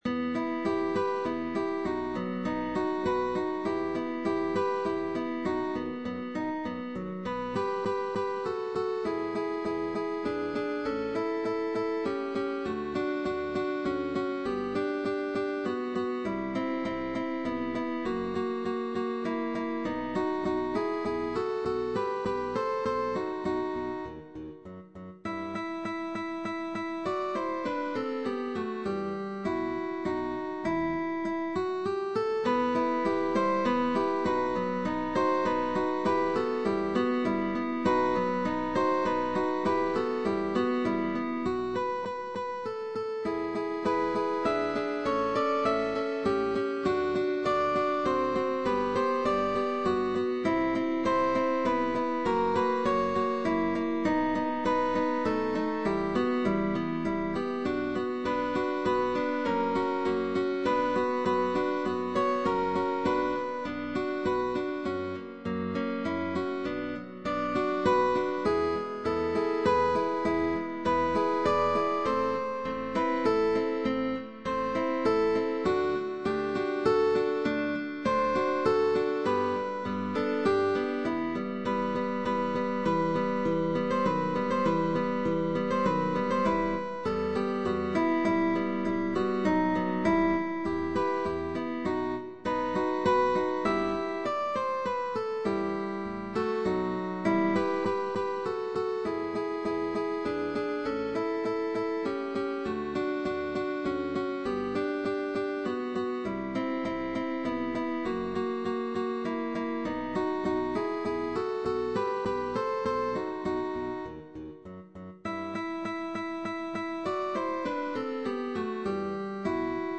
GUITAR QUARTET Sheetmusic
operatic aria
Tag: Baroque